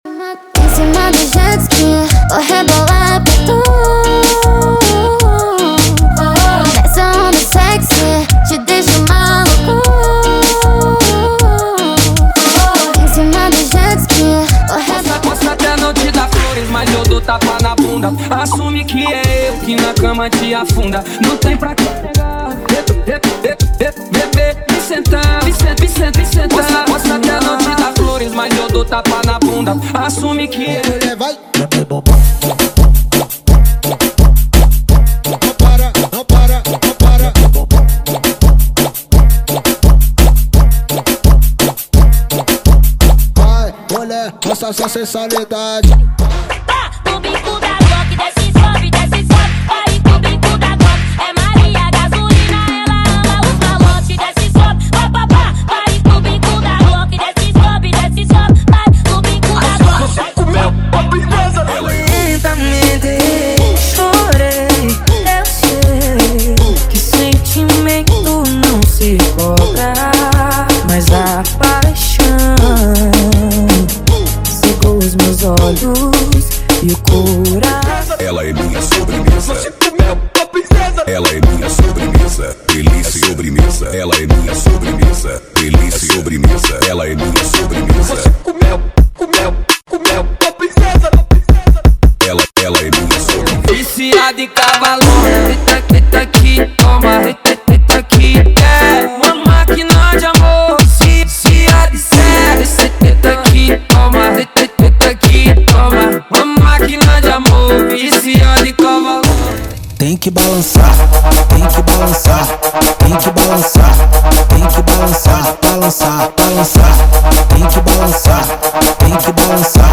Os Melhores Brega Funk do momento estão aqui!!!
• Brega Funk = 50 Músicas
• Sem Vinhetas
• Em Alta Qualidade